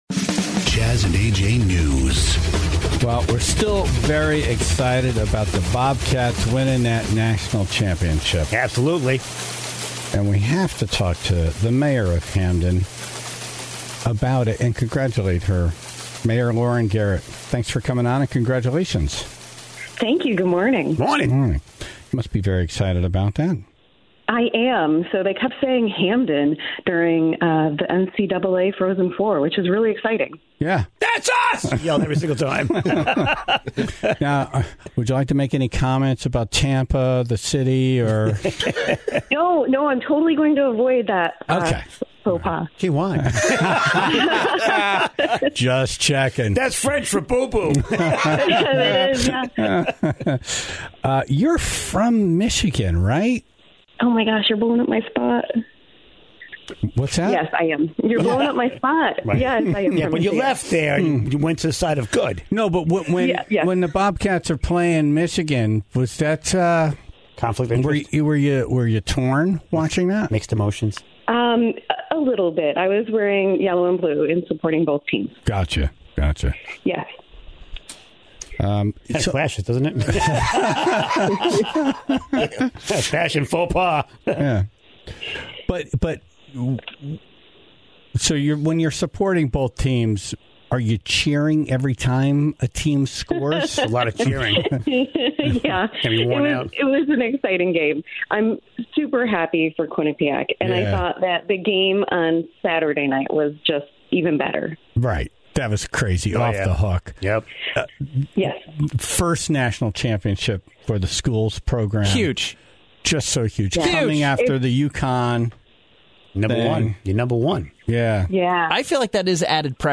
on the phone